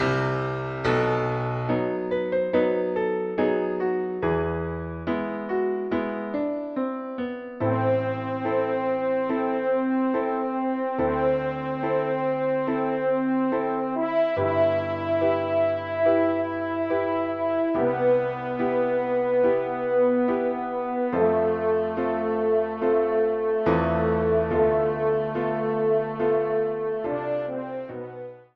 Transcription initiale et arrangement pour cor en ré
Genre :  Religieux
ENSEMBLE